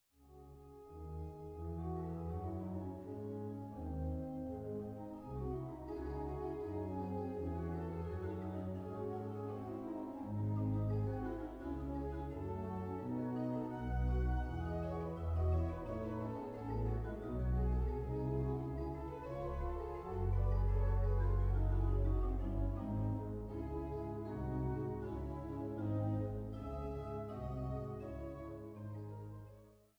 Trost-Orgel der Schlosskirche Altenburg
Naturtrompete